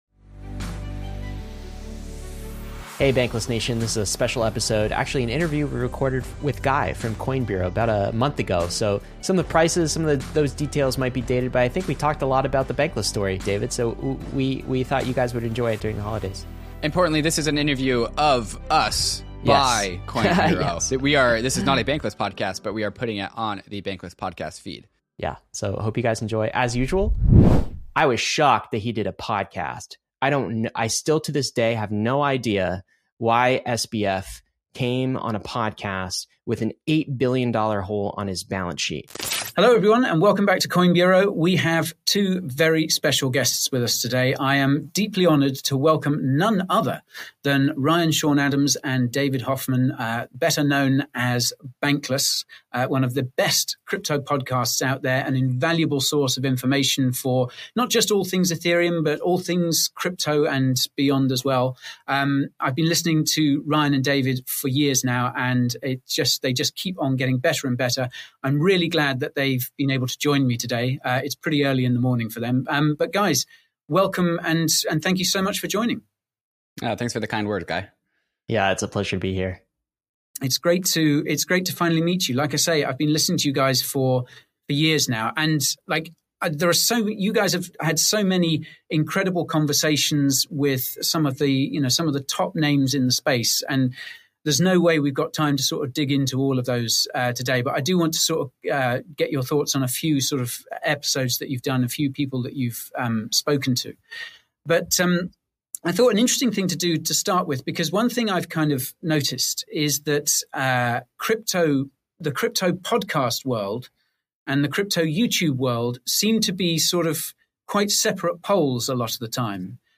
meta-conversation